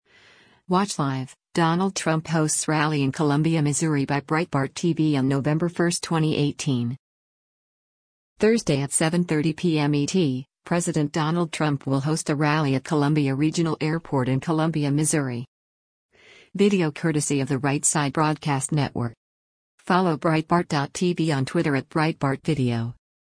Thursday at 7:30 p.m. ET, President Donald Trump will host a rally at Columbia Regional Airport in Columbia, MO.